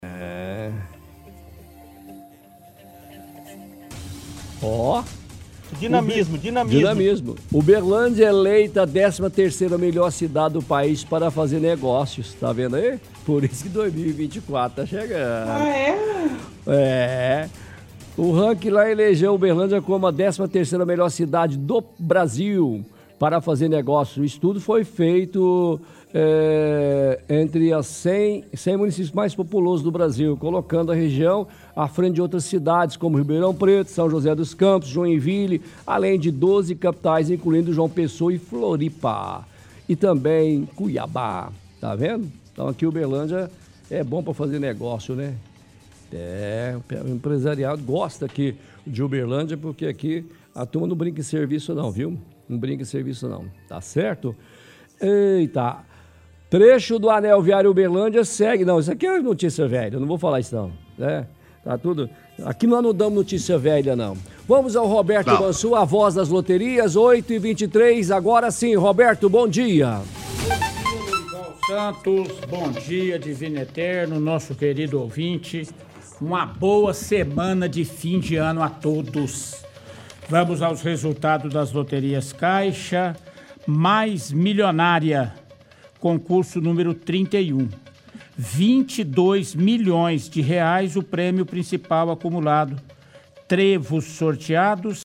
lê matéria, sem citar a fonte, afirmando que Uberlândia é a 13º melhor cidade para fazer negócios.